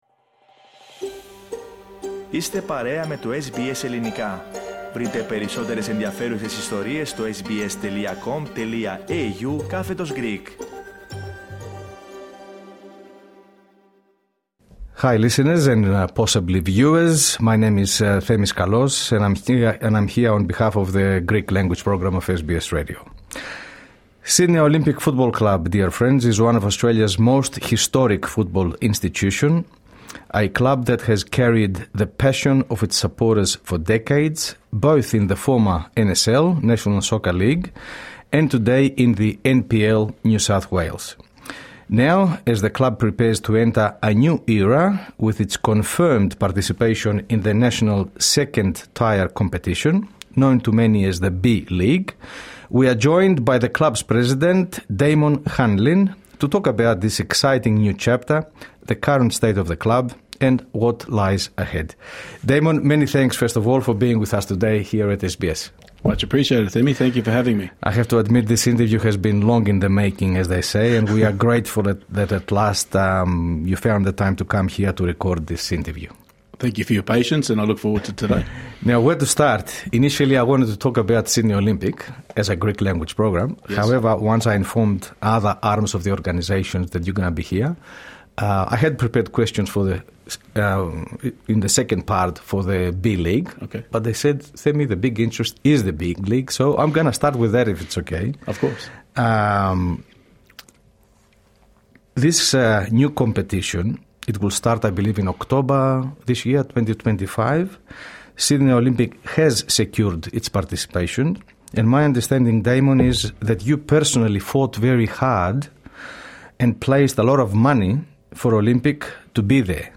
In an interview long in the making